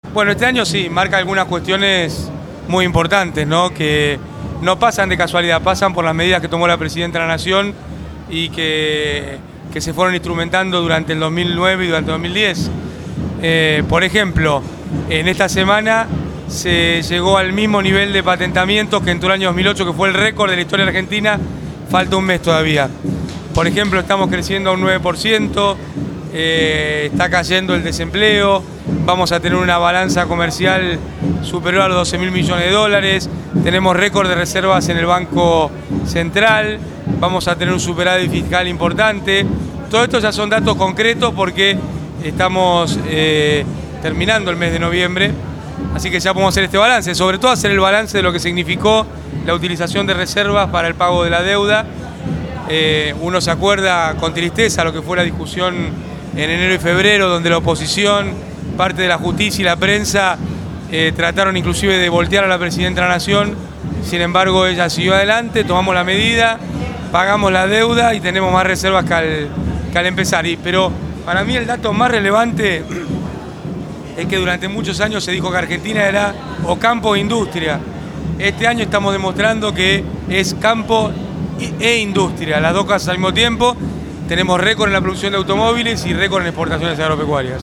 Al cumplirse un mes de su fallecimiento, La Cámpora organizó en la ciudad de La Plata un acto central en homenaje al ex Presidente.